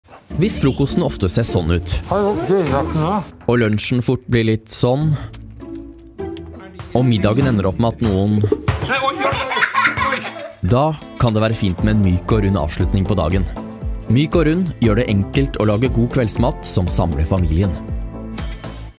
Male
Corporate, Energetic, Friendly, Warm, Young
My home studio gear delivers great sound quality.
My voice sounds relatively young. It has a friendly, crisp and trustworthy touch.
Microphone: Neuman TLM 103